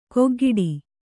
♪ koggiḍi